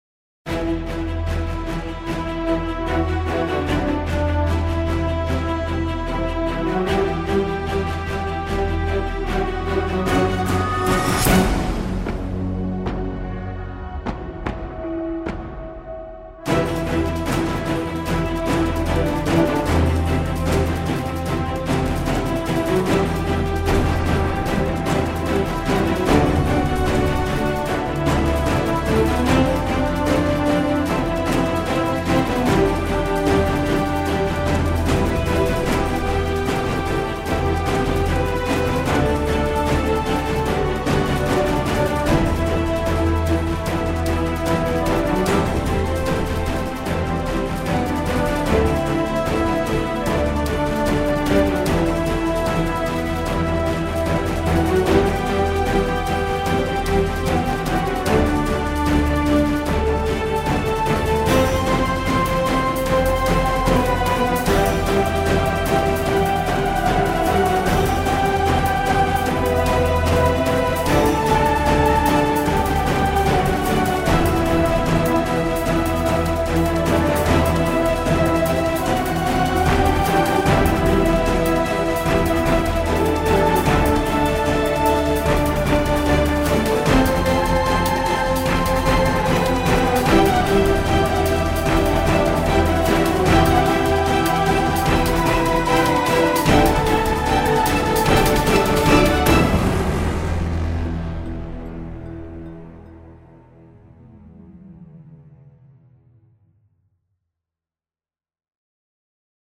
Epic orchestral track for trailers and RPG.